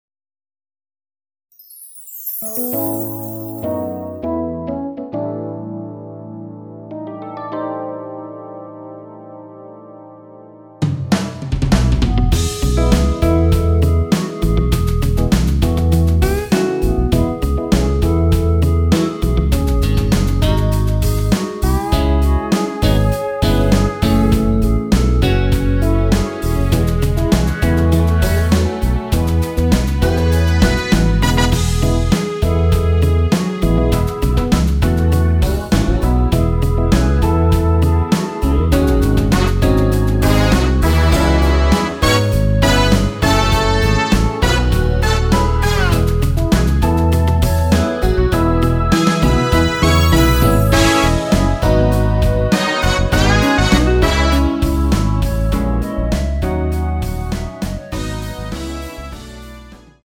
원키에서(-1)내린 멜로디 포함된 MR입니다.(미리듣기 확인)
◈ 곡명 옆 (-1)은 반음 내림, (+1)은 반음 올림 입니다.
앞부분30초, 뒷부분30초씩 편집해서 올려 드리고 있습니다.
중간에 음이 끈어지고 다시 나오는 이유는